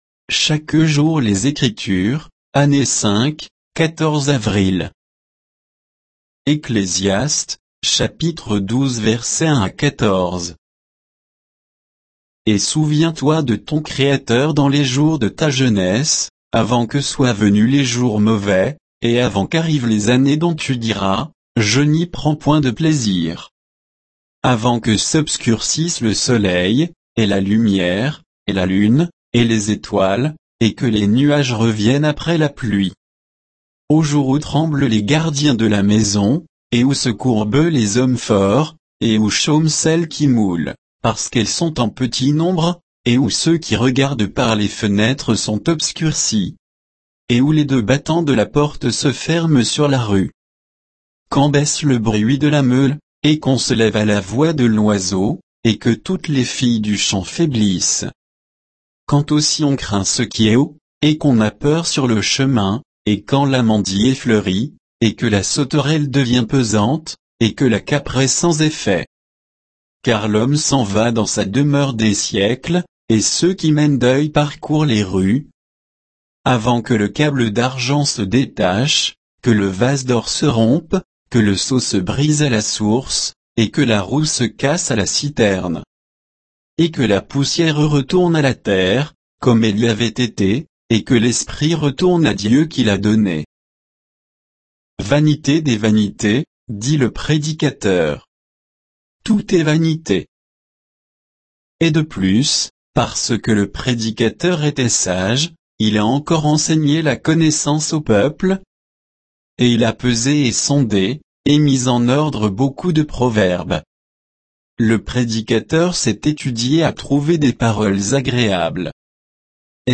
Méditation quoditienne de Chaque jour les Écritures sur Ecclésiaste 12